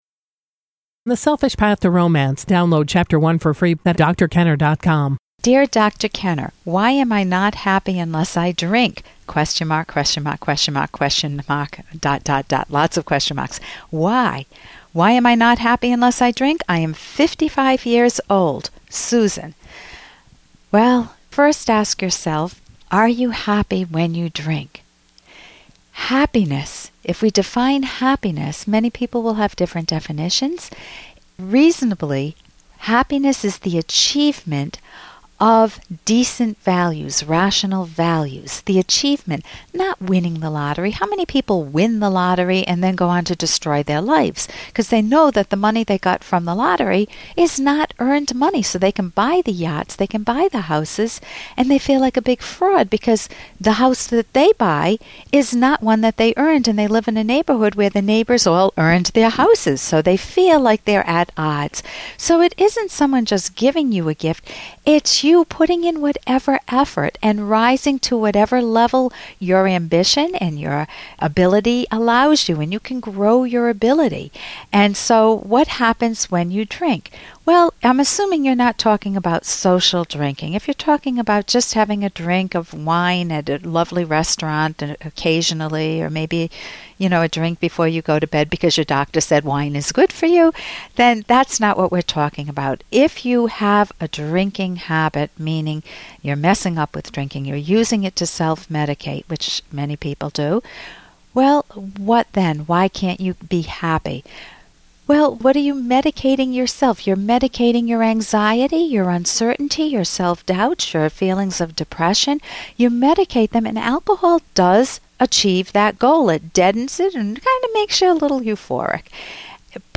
Listen to caller's personal dramas four times each week